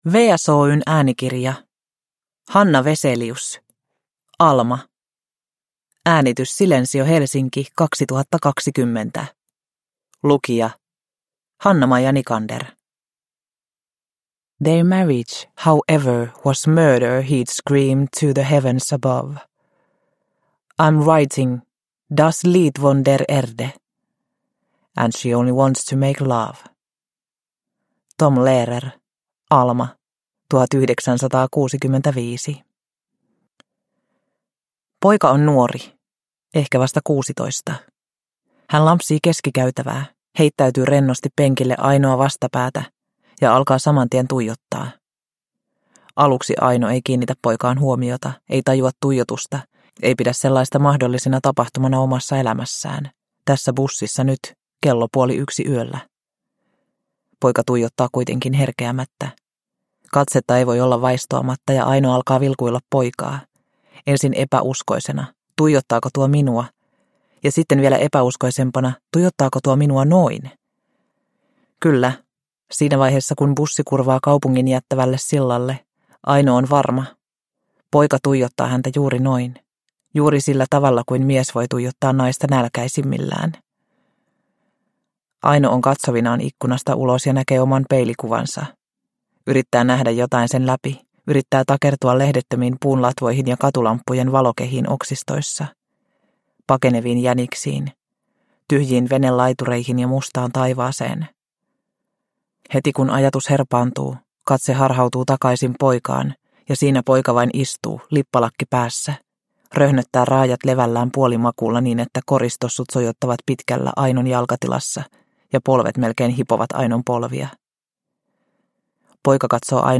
Alma! – Ljudbok – Laddas ner